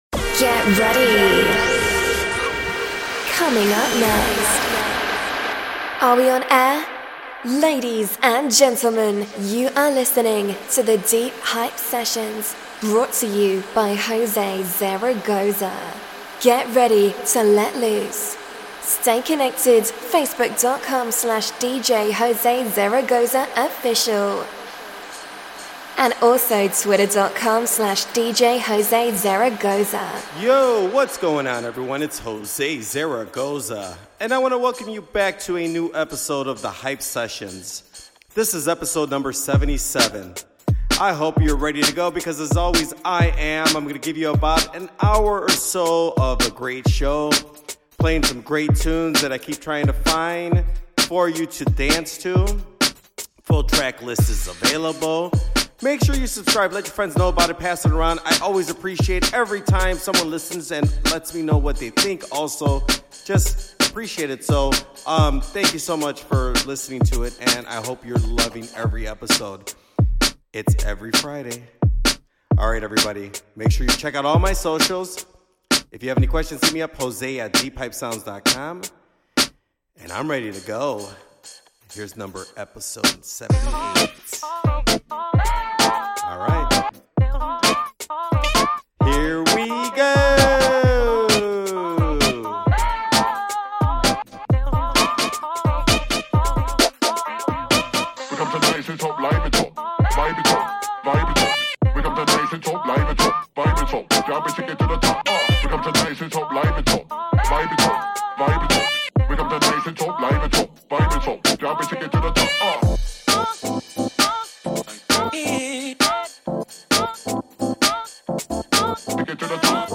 A full hour of fun music.